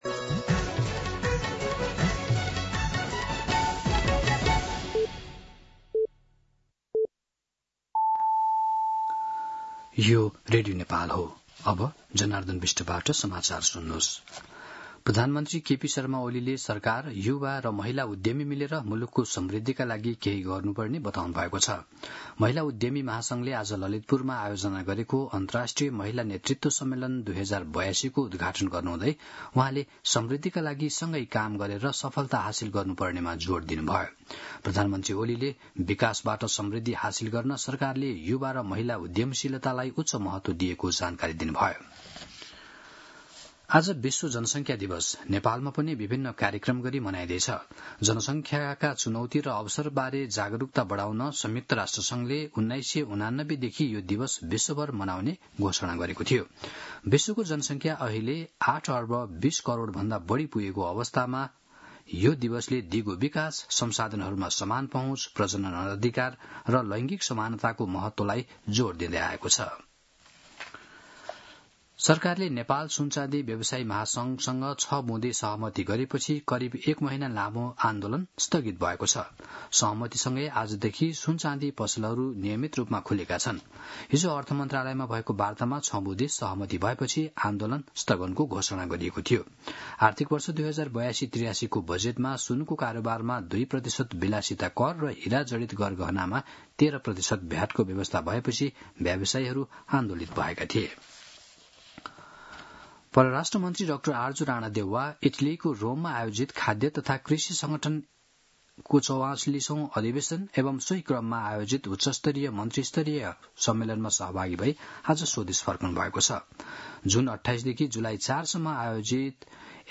मध्यान्ह १२ बजेको नेपाली समाचार : २७ असार , २०८२
12-pm-News-3-27.mp3